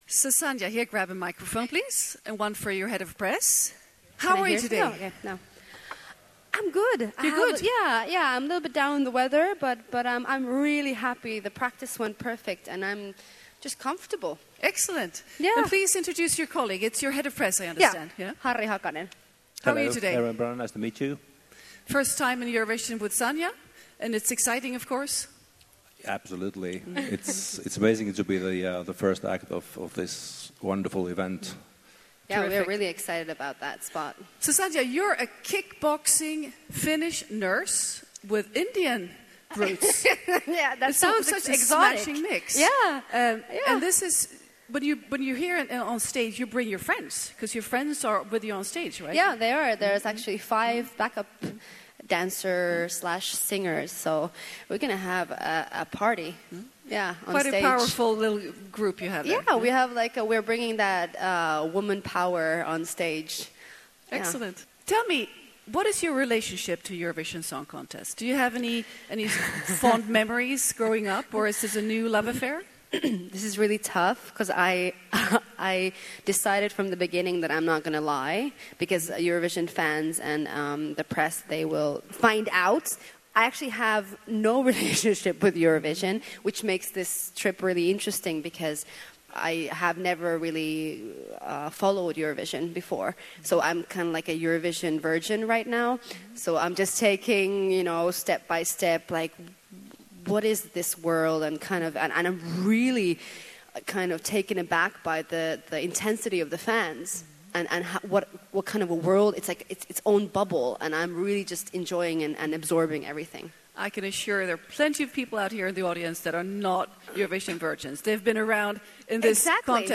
Sandhja Eurviisujen lehdistötilaisuudessa